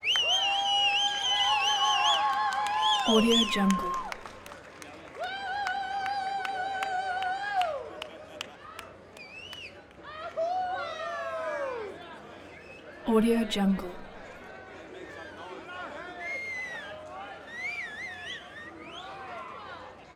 دانلود افکت صوتی سوت و فریاد و همهمه تماشاگران ورزشی